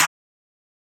{Snare} Mafia1.wav